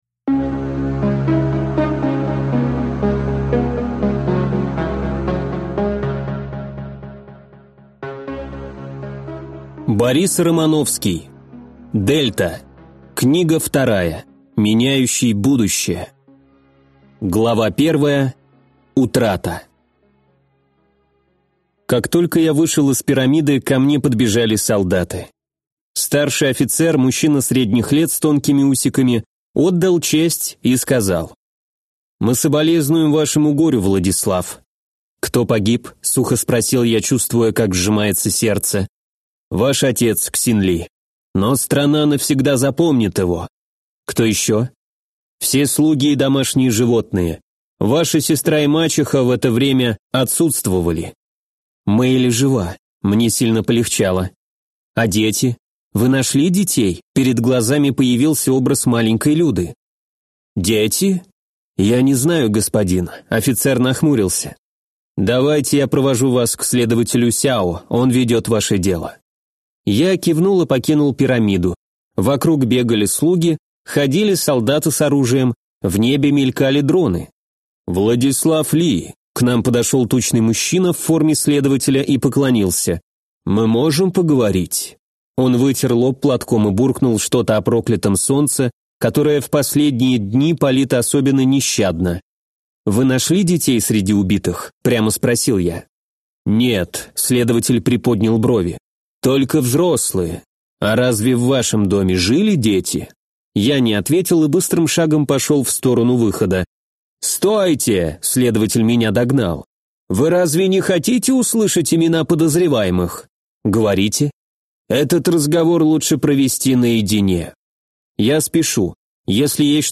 Аудиокнига Дельта. Книга 2. Меняющий будущее | Библиотека аудиокниг